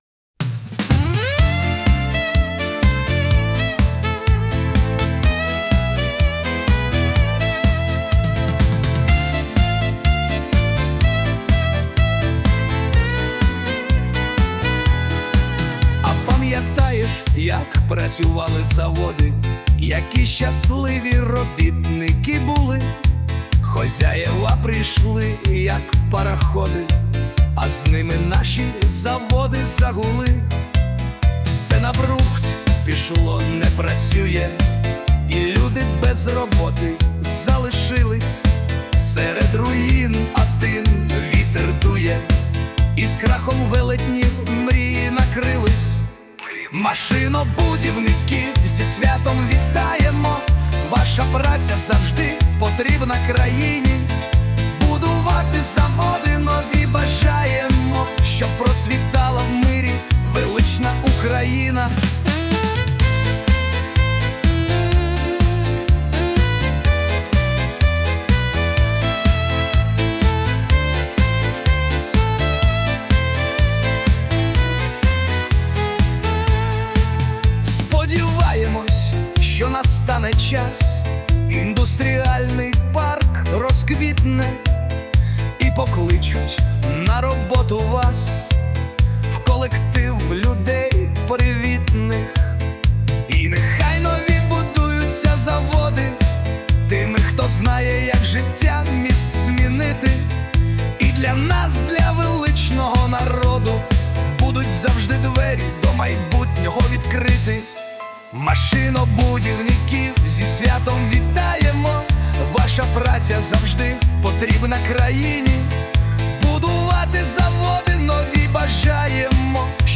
ТИП: Пісня